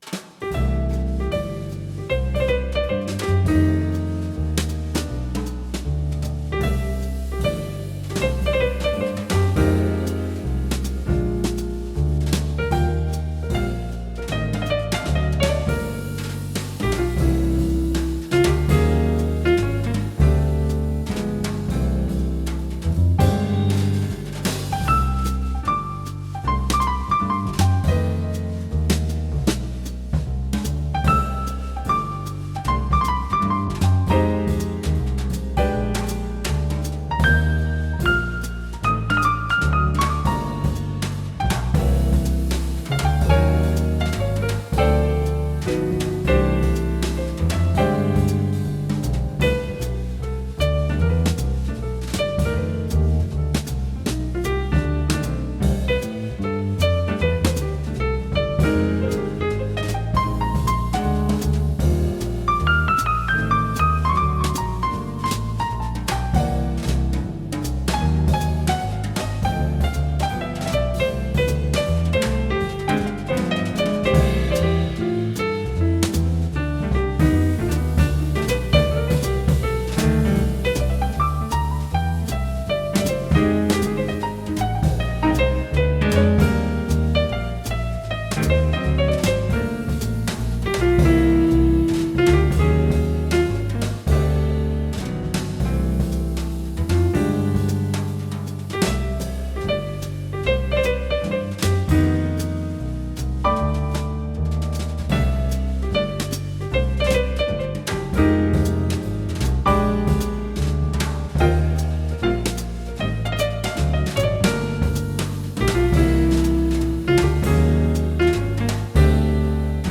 **some jazzy tunes for you **